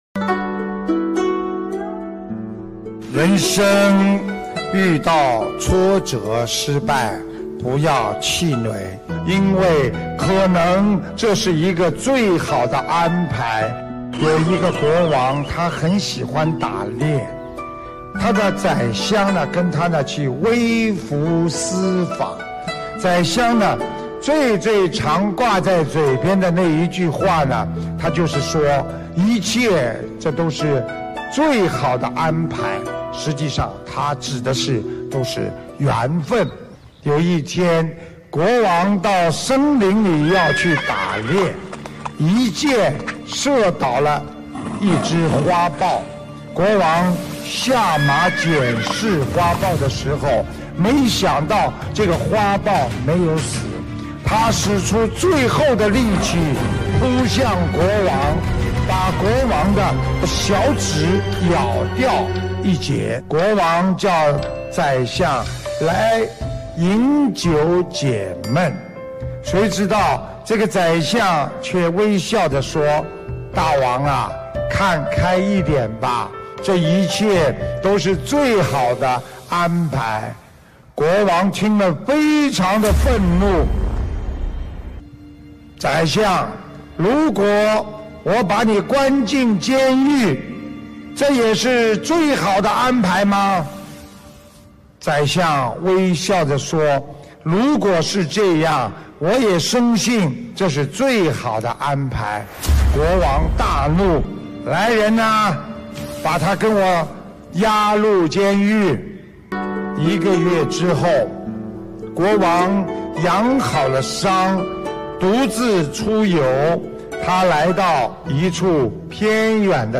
师父讲述佛教小故事！